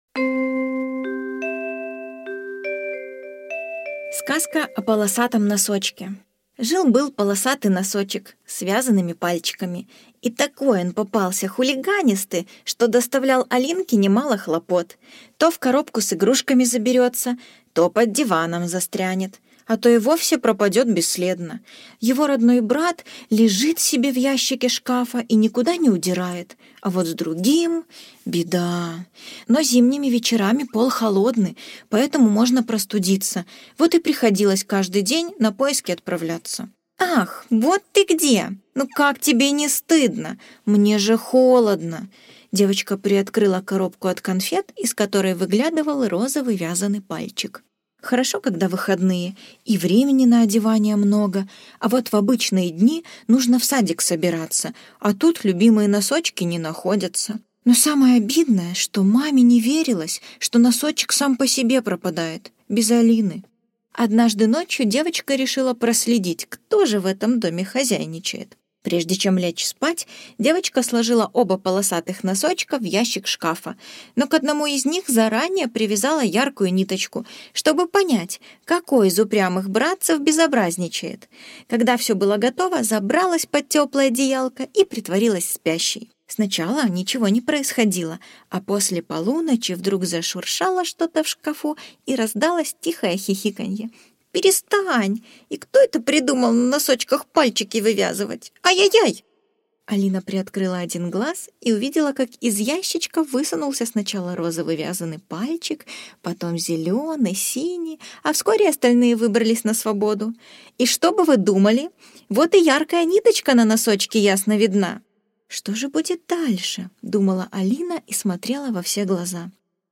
Сказка о полосатом носочке - аудиосказка Власенко - слушать онлайн